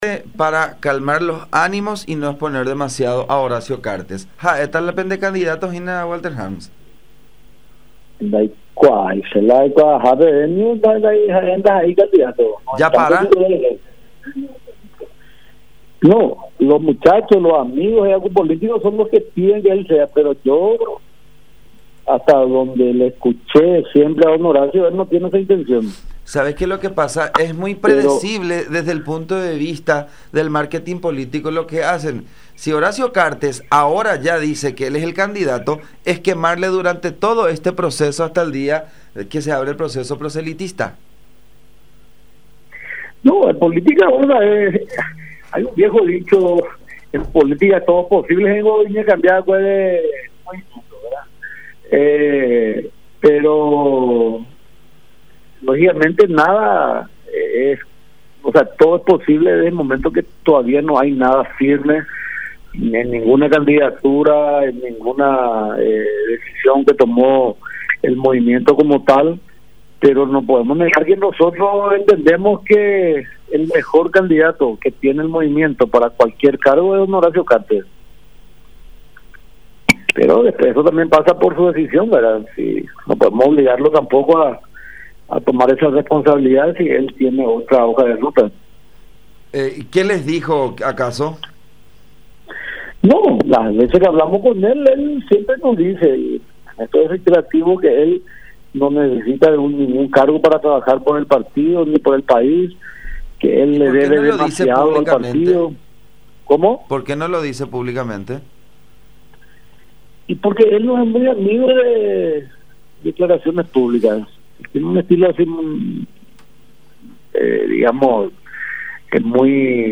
“Los amigos y compañeros son los que les piden, pero yo, hasta donde le escuché a Horacio, él no tiene esa intención”, expuso el legislador en comunicación con La Unión.